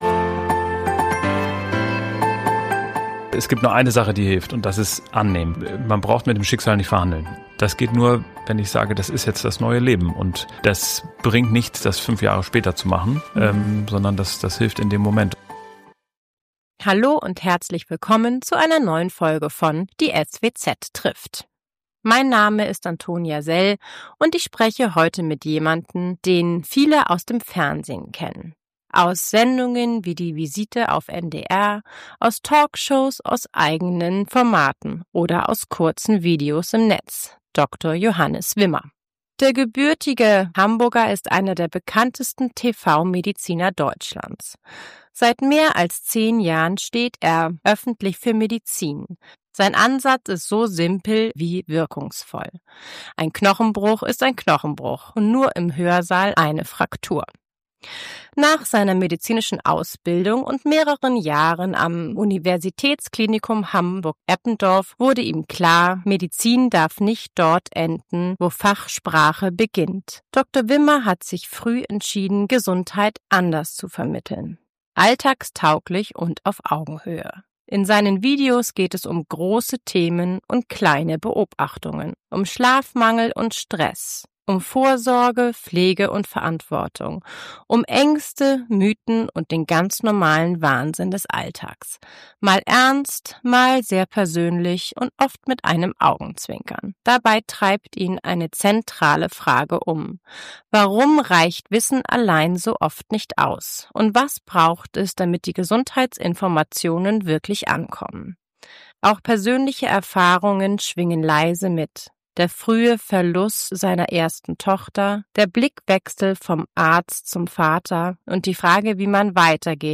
Diesmal zu Gast: Dr. Johannes Wimmer, TV-Arzt, Unternehmer, Content-Creator